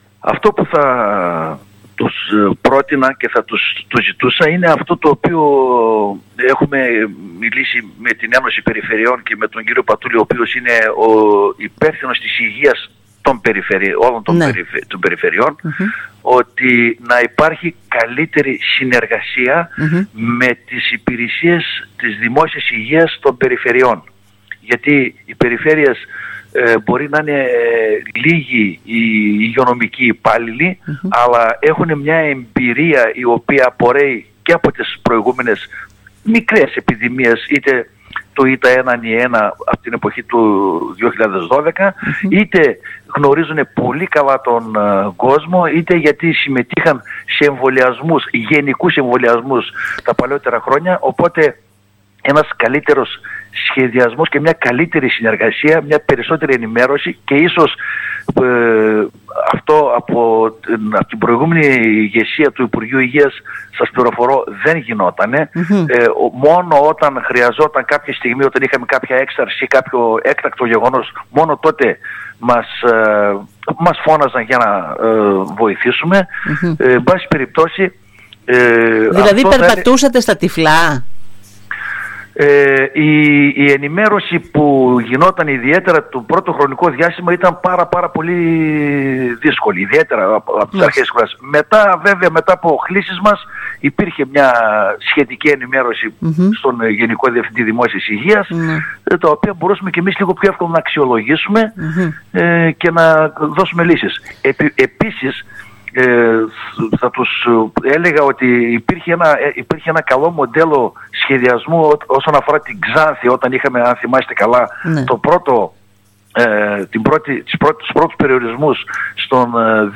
Την επιθυμία ύπαρξης καλύτερης συνεργασίας με τις υπηρεσίες Δημόσιας Υγείας των Περιφερειών εξέφρασε ο Αντιπεριφερειάρχης Υγείας ΑΜΘ Κώστας Βενετίδης μιλώντας στην ΕΡΤ Ορεστιάδας.